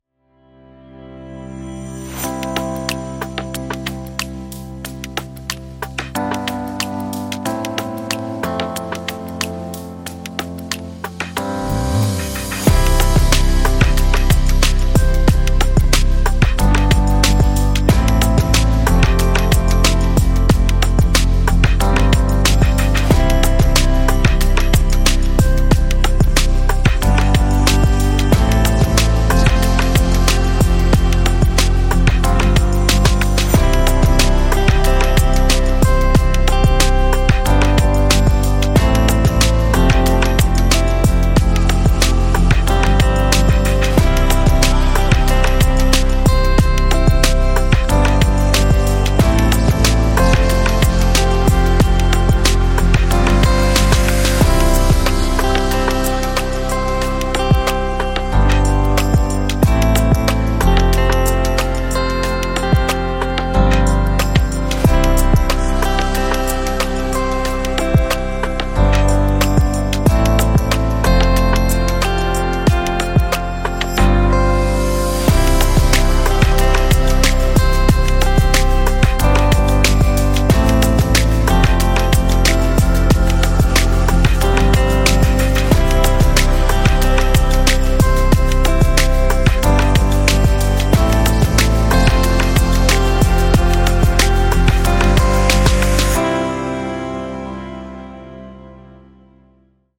10 - Warm Relax Hip-Hop Beat